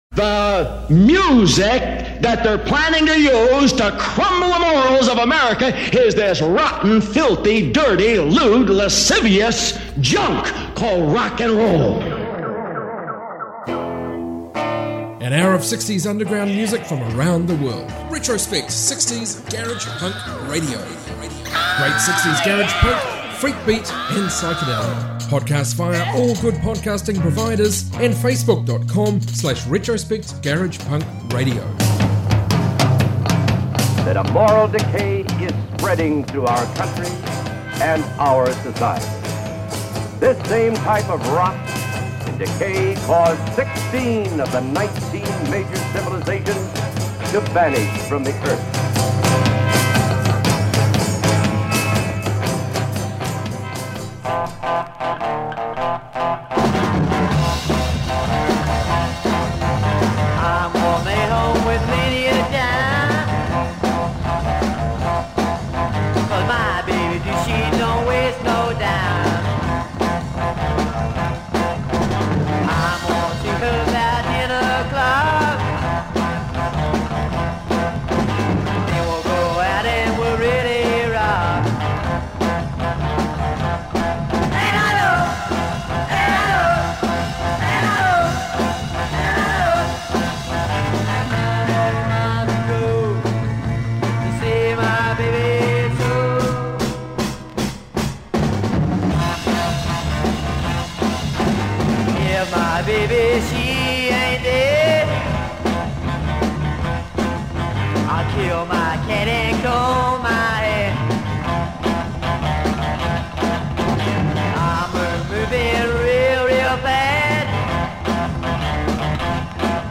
60s garage